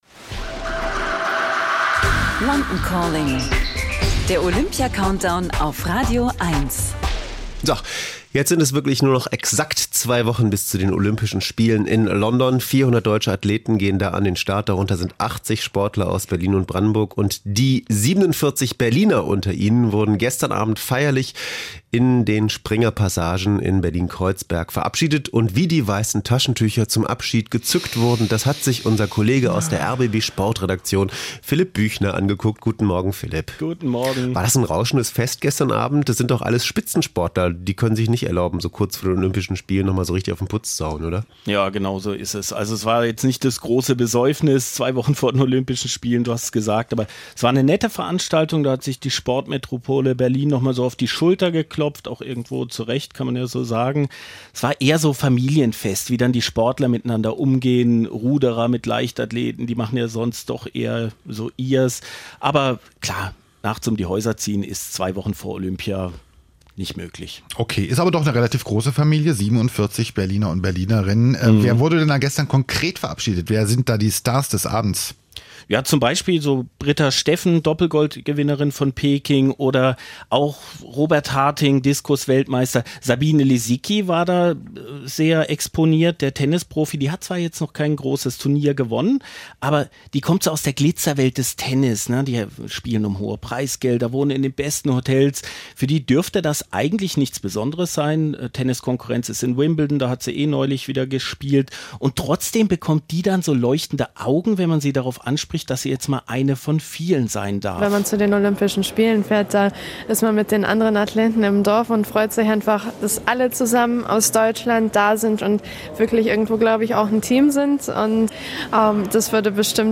Hier ein kurzes Studiogespräch von RadioEins (RBB) zur offiziellen Verabschiedung der Berliner Olympioniken durch den Olympiastützpunkt Berlin am 12.07.2012. Viel Spaß beim Reinhören.